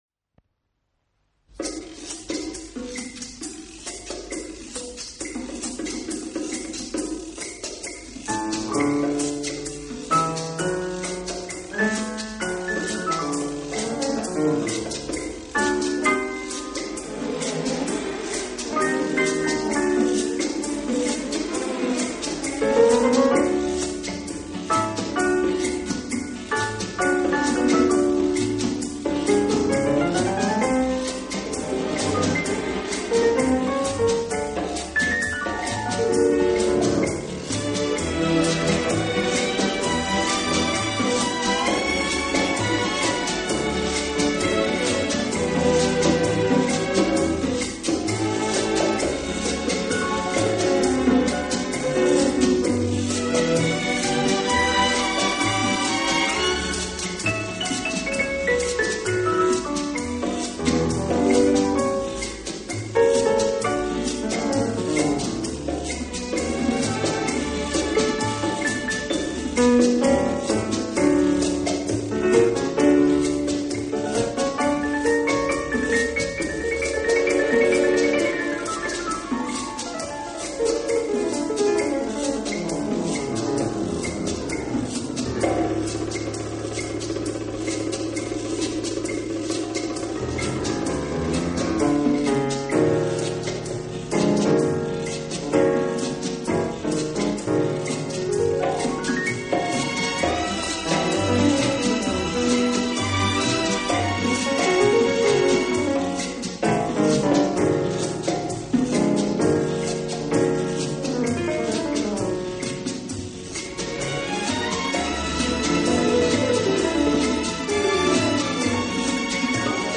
【轻音乐专辑】
演奏以轻音乐和舞曲为主。